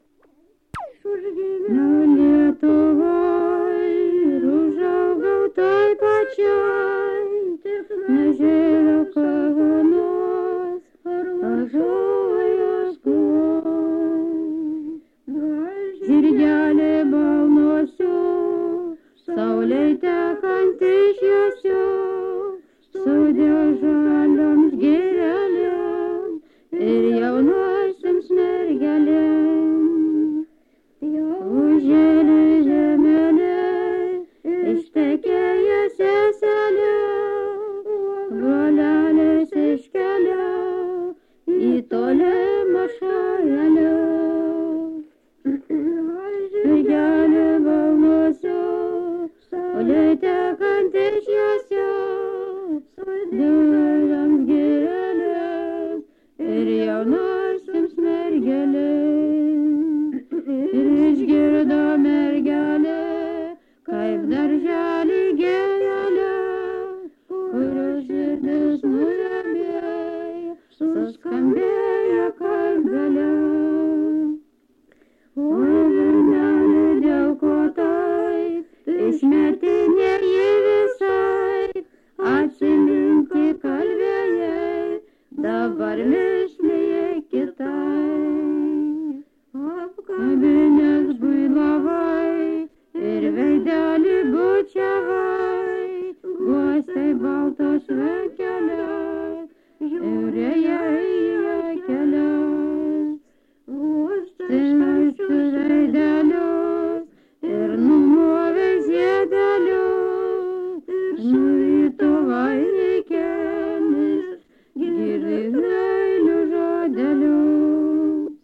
vokalinis
balbataika